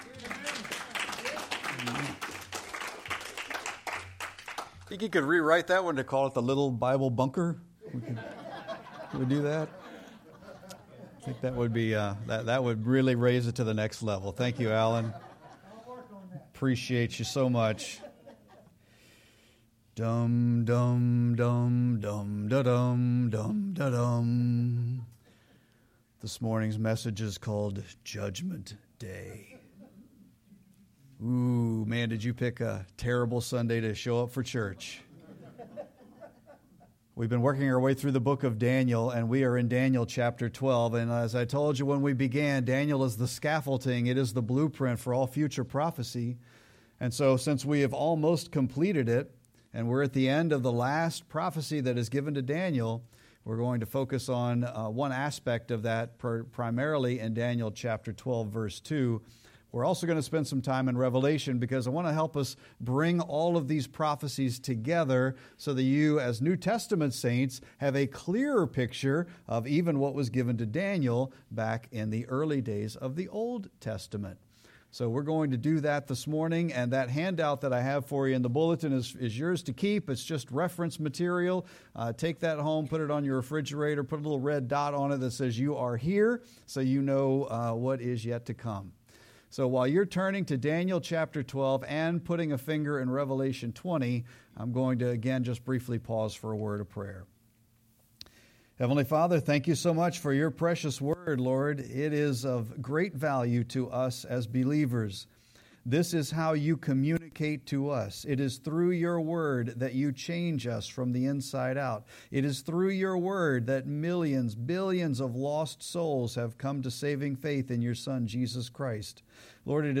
Sermon-3-30-25.mp3